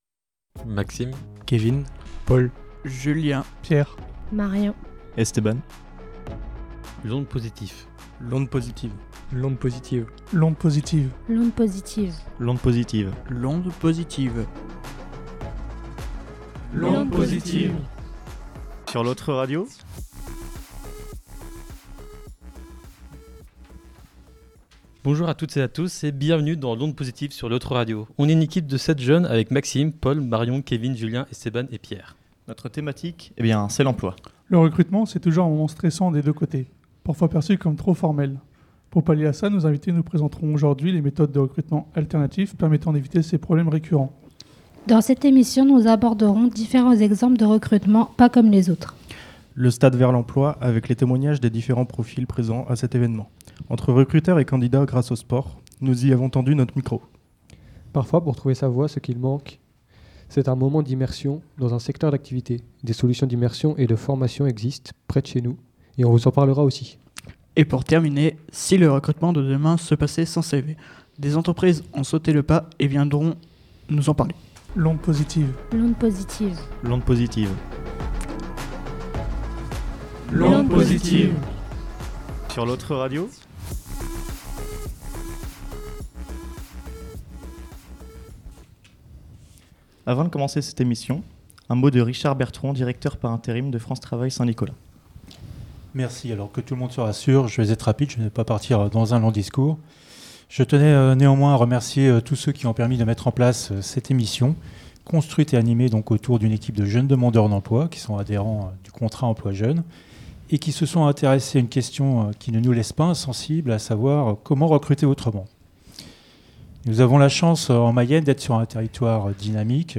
Préparer une émission radio sur l’emploi qui a été enregistrée en public le jeudi 28 novembre en présence de différents invités